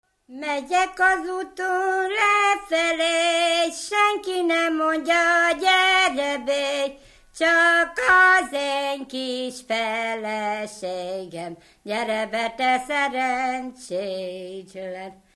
Alföld - Szabolcs vm. - Aradványpuszta (Nyíradony)
Stílus: 1.1. Ereszkedő kvintváltó pentaton dallamok
Szótagszám: 8.8.8.8
Kadencia: 7 (5) VII 1